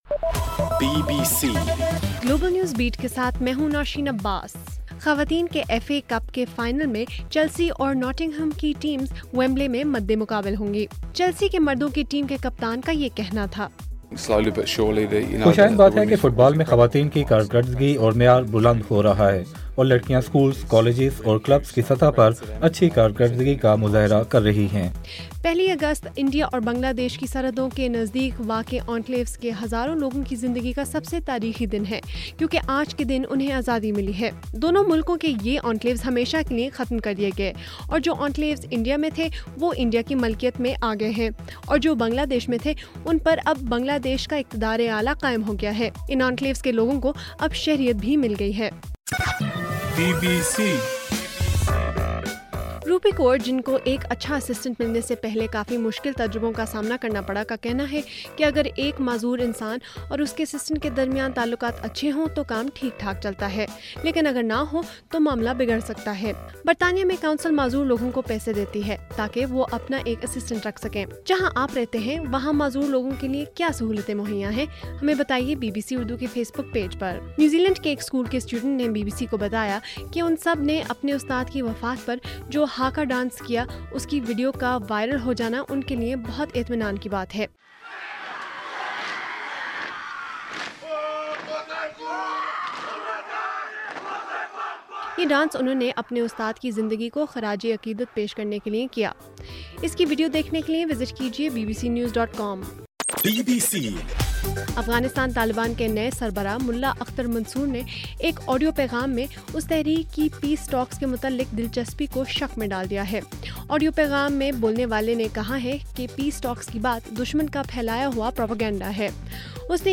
اگست 1: رات 11 بجے کا گلوبل نیوز بیٹ بُلیٹن